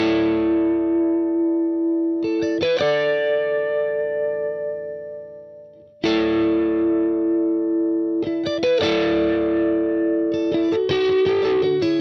标签： 80 bpm Pop Loops Guitar Electric Loops 2.02 MB wav Key : A
声道立体声